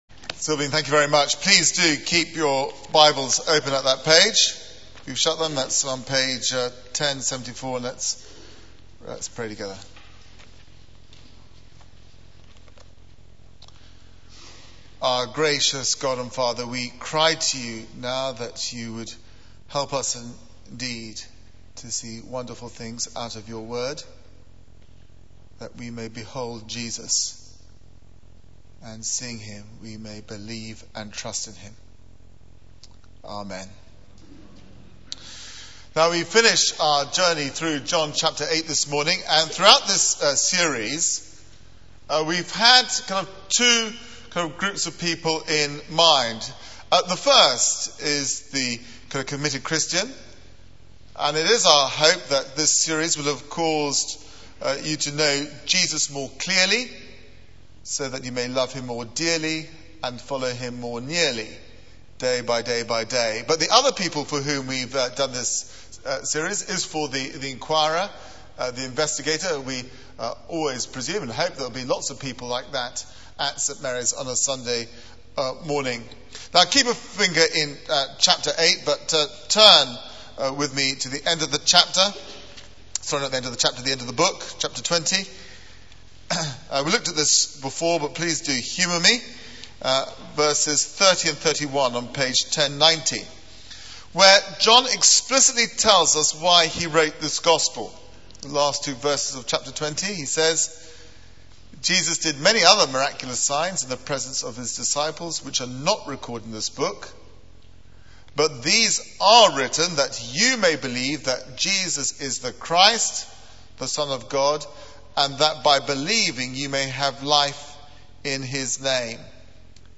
St Mary's Church Maidenhead
Media for 9:15am Service on Sun 17th May 2009 09:15 Speaker: Passage: John 8: 48-59 Series: Claims to be Stoned For Theme: The Great "I AM" Sermon Search the media library There are recordings here going back several years.